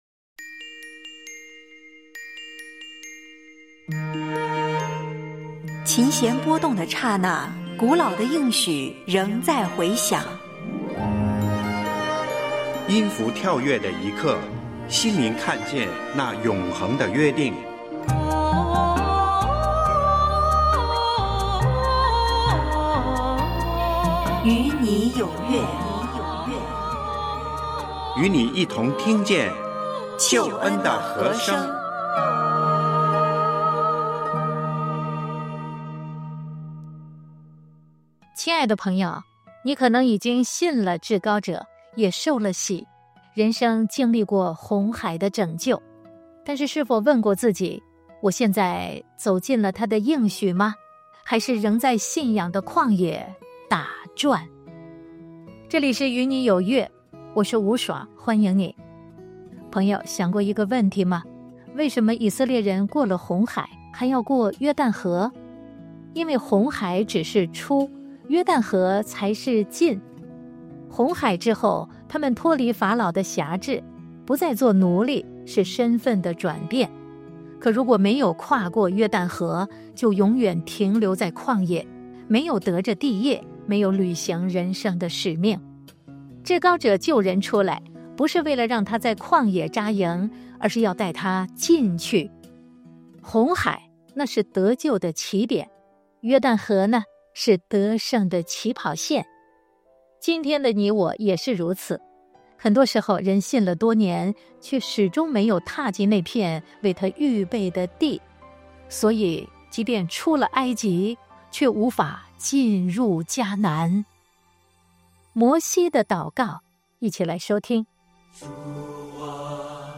从红海到约旦河：常问自己──我出埃及了，可进入迦南了么？诗歌：《摩西的祷告》、《跨越的信心》、《不动摇的信心》、《安静》、《顺服》